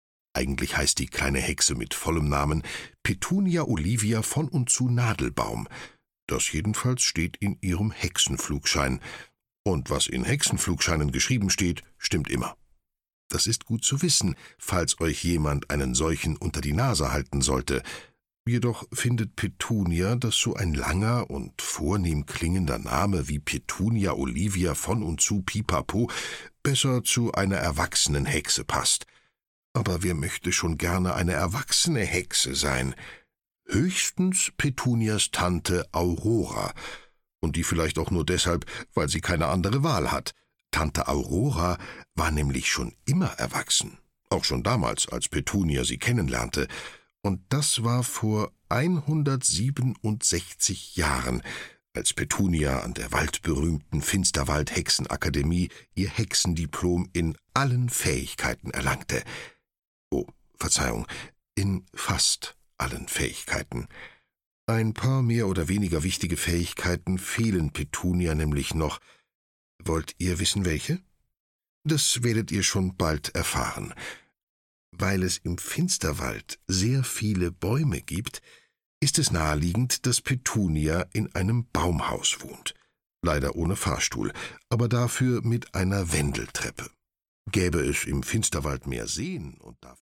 Willkommen im Zauberwald - Katja Alves - Hörbuch